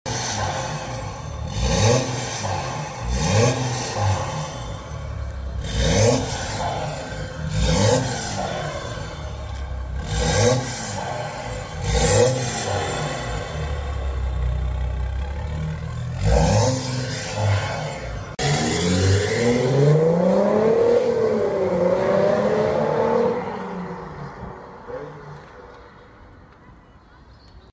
Audi Q5 3.0l Dpf Delete Sound Effects Free Download
Audi Q5 3.0l Dpf Delete & Custom.Backbox Deletes.